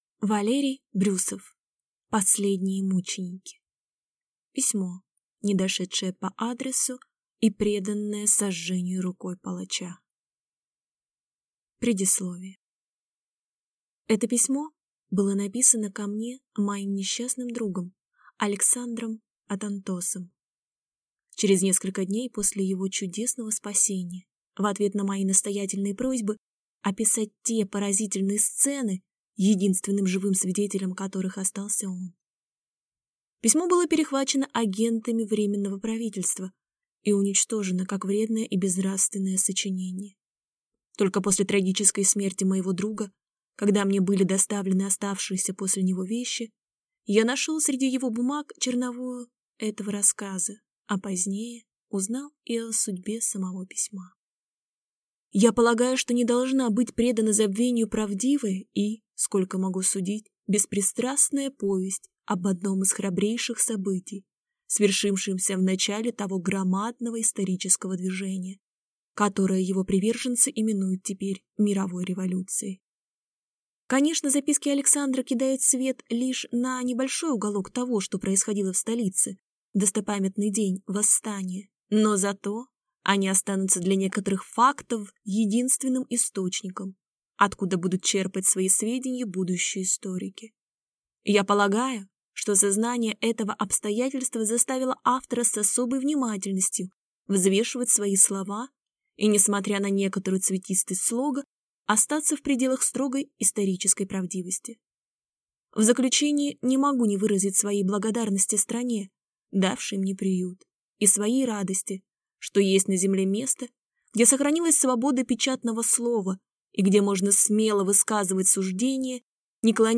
Аудиокнига Последние мученики | Библиотека аудиокниг